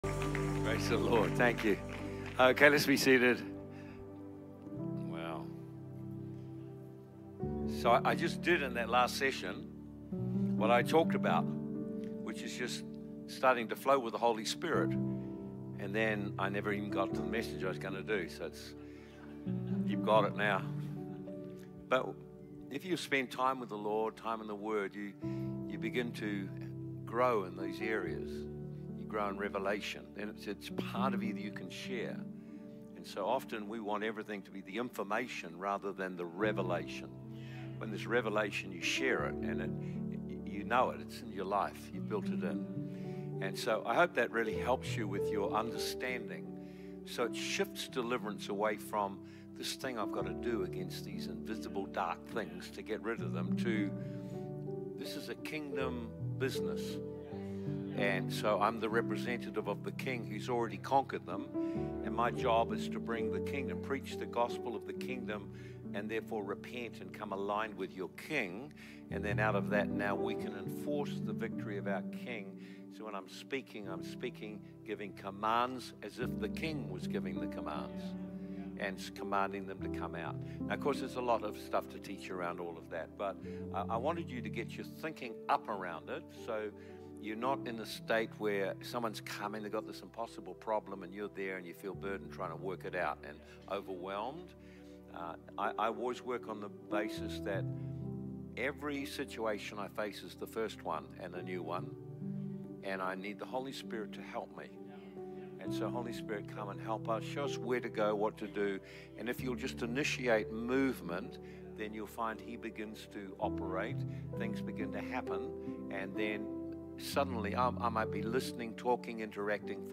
Recorded at Nations Church, Perth